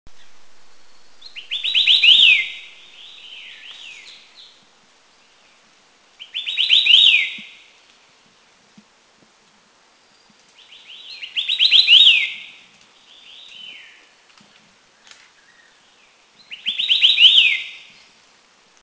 White-eared Sibia
Heterophasia auricularis
White-earedSibia.mp3